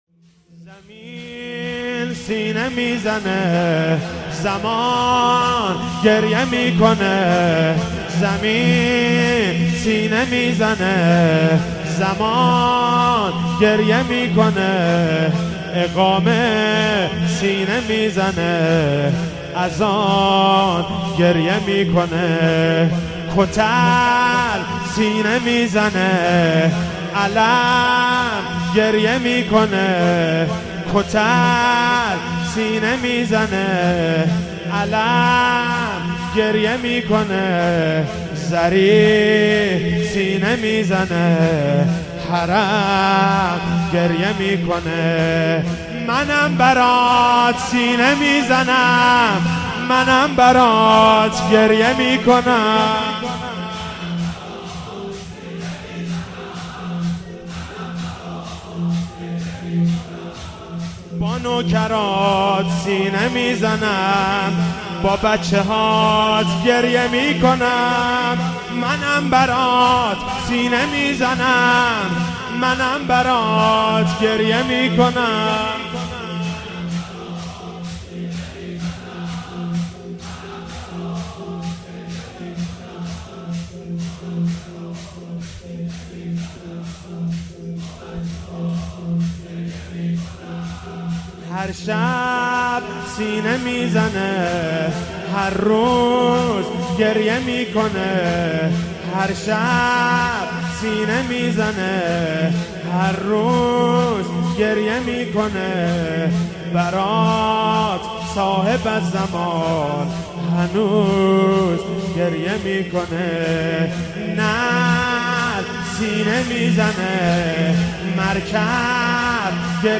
هفتگی 10 بهمن 96 - شور - زمین سینه میزنه زمان گریه میکنه